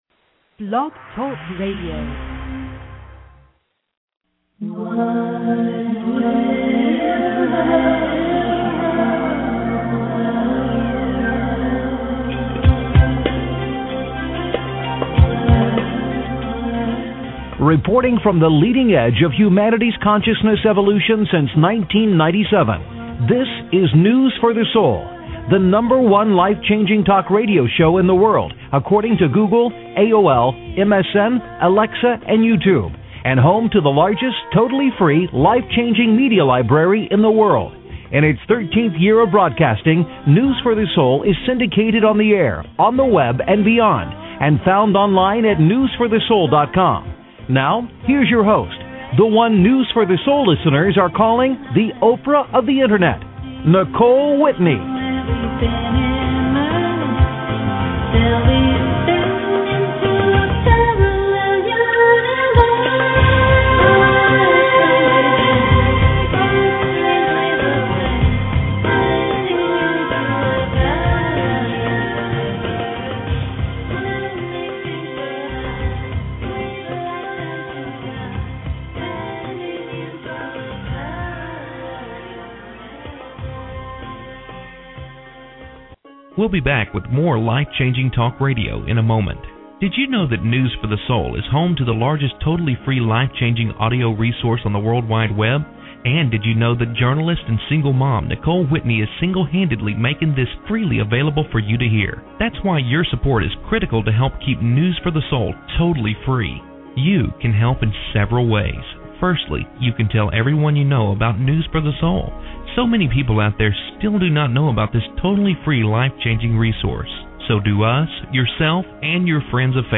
News for the Soul Interview